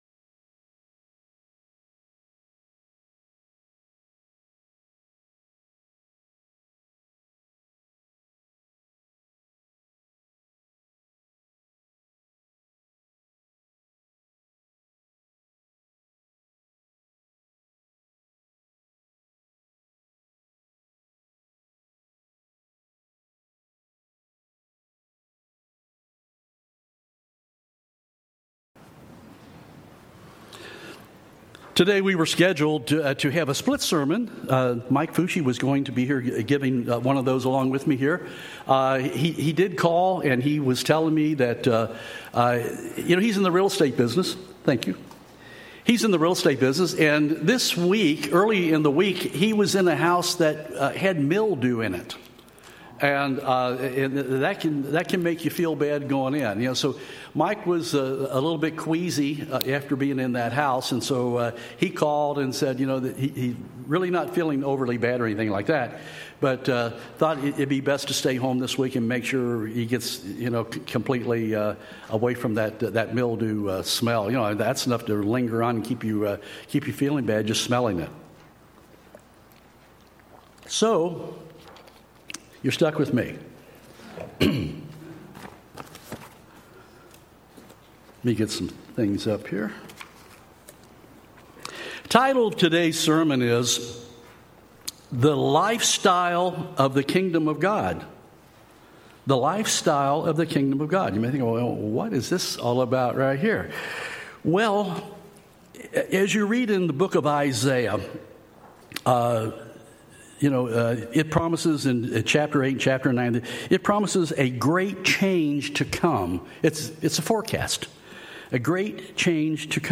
Given in Nashville, TN